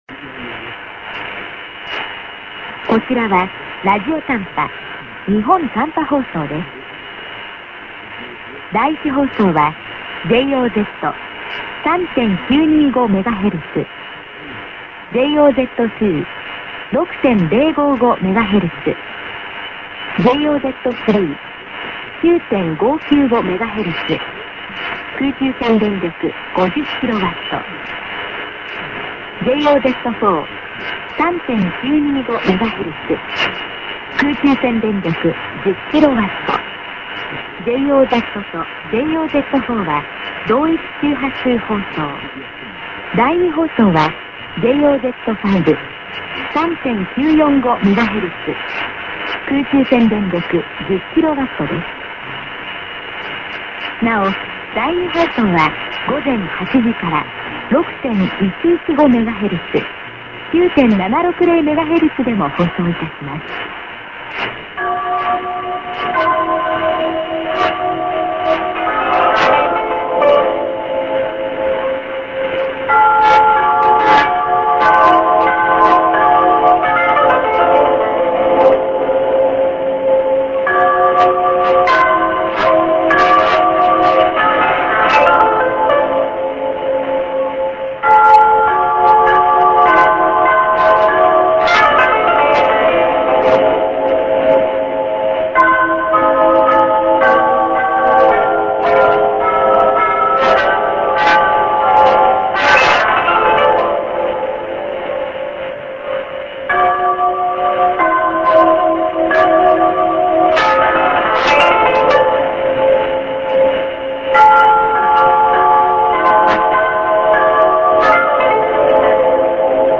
St. ID+SKJ(women)->music(koto)->03'55":ID+SKJ(women)->　SKJ=NSB1+NSB2　check 3,925 for better